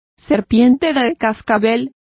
Complete with pronunciation of the translation of rattler.